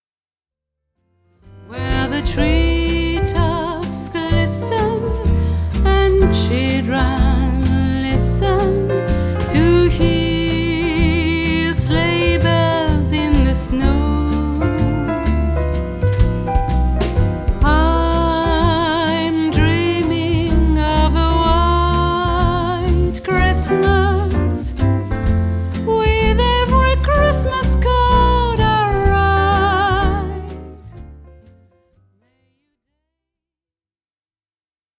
Les grands classiques de Noël
voix cristalline